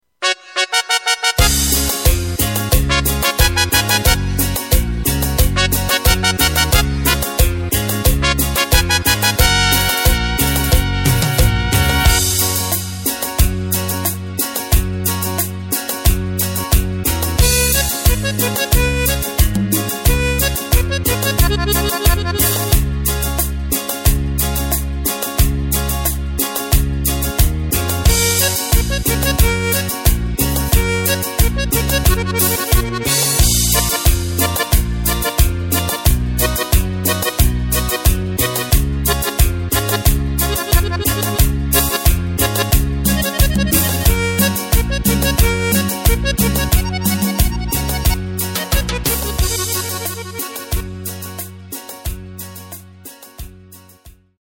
Takt: 4/4 Tempo: 180.00 Tonart: Gb
Salsa
mp3 Playback Demo